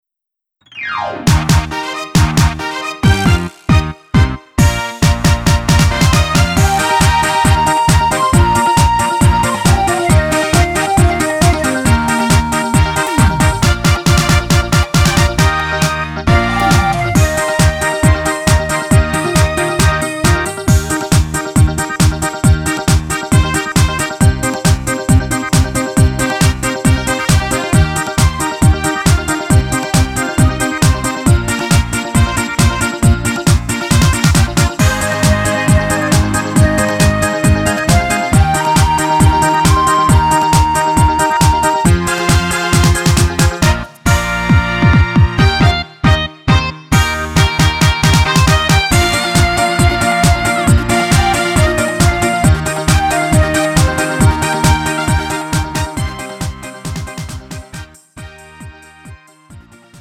음정 원키 3:29
장르 구분 Lite MR